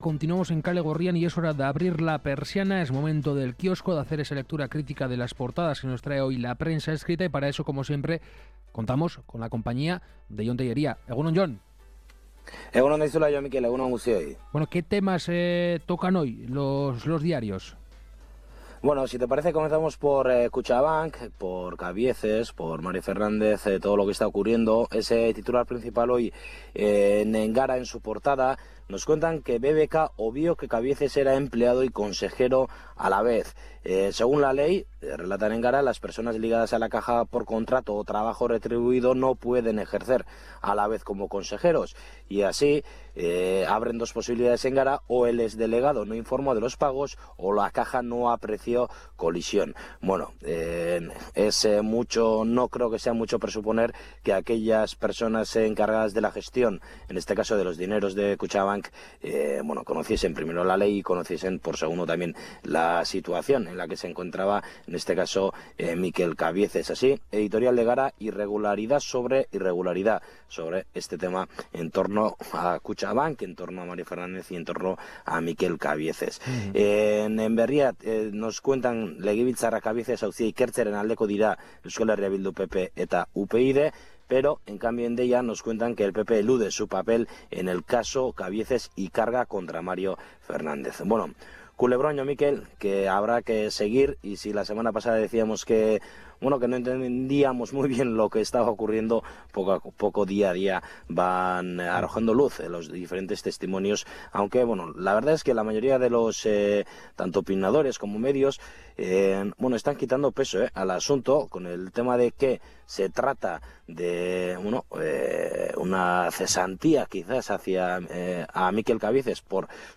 Puedes seguir la lectura de prensa mientras observas las portadas del día.